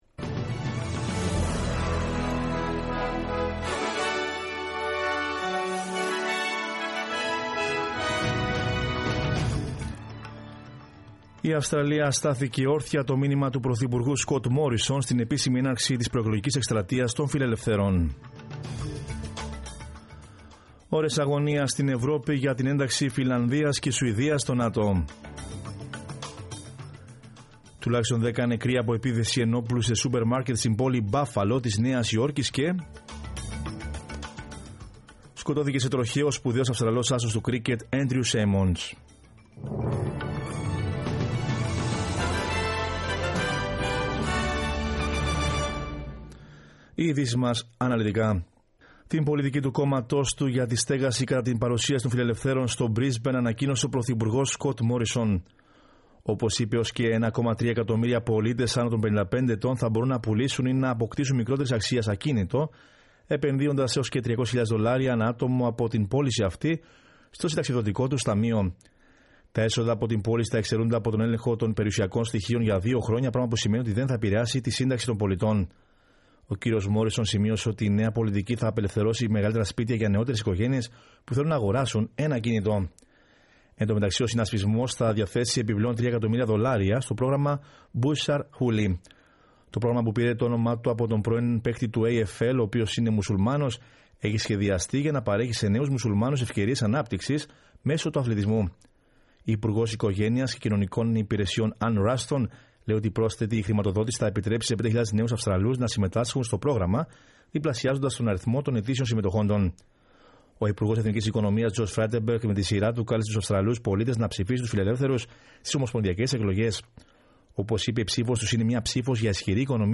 News in Greek from Australia, Greece, Cyprus and the world is the news bulletin of Sunday 15 May 2022.